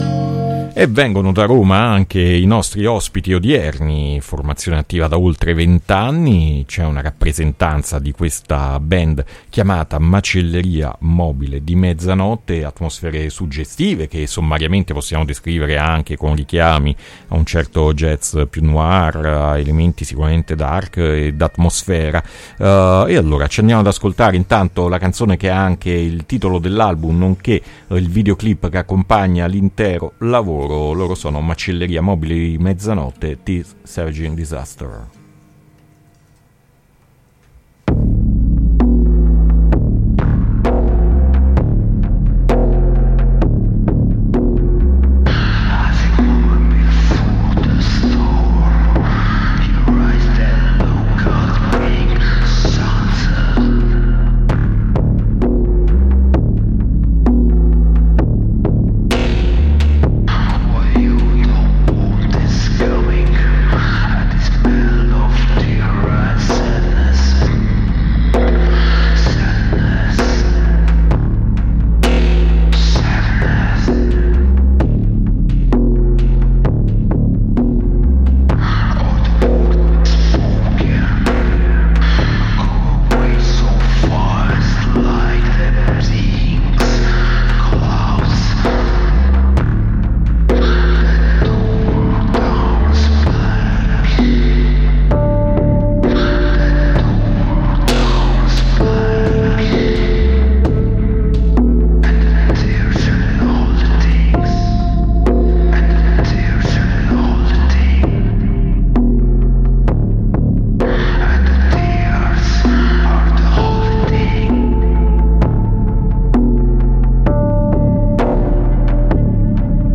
Intervista Macelleria Mobile di Mezzanotte a Puzzle 4-7-2022